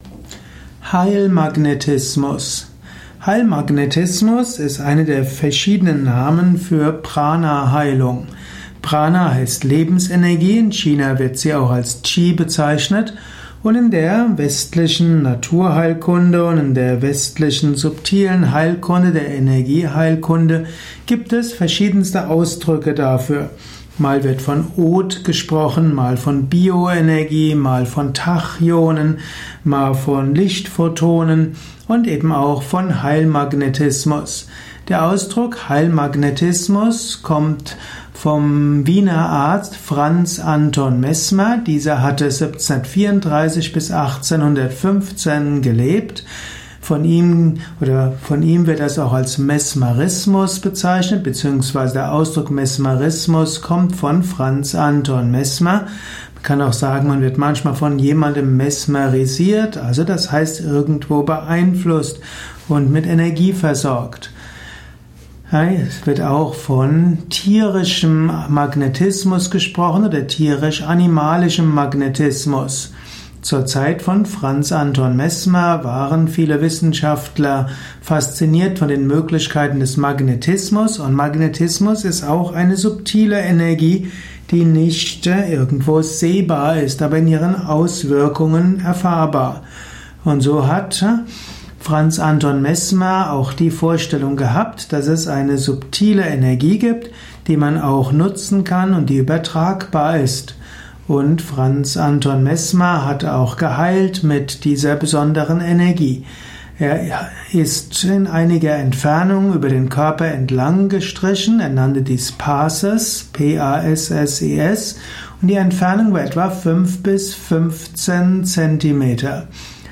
Vortragsaudio rund um das Thema Heilmagnetismus. Erfahre einiges zum Thema Heilmagnetismus in diesem kurzen Improvisations-Vortrag.